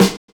Snare set 2 008.wav